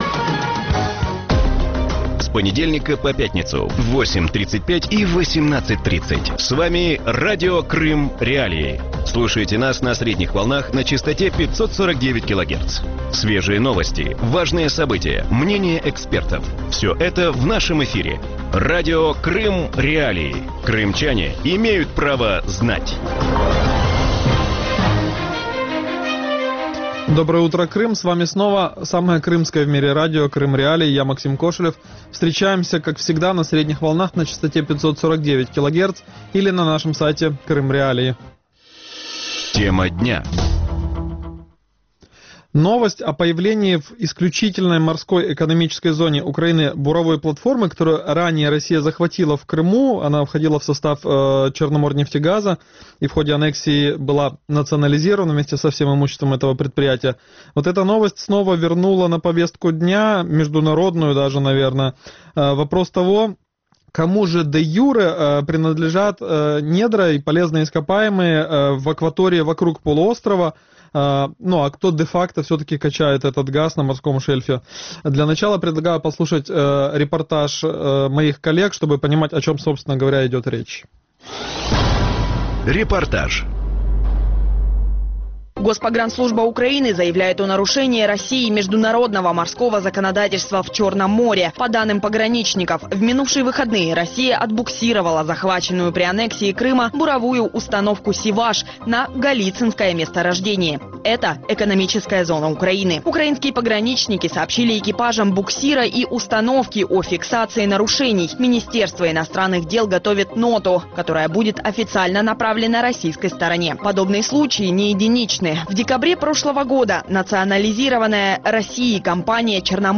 Утром в эфире Радио Крым.Реалии говорят о конфликте вокруг недр черноморского шельфа после аннексии Крыма.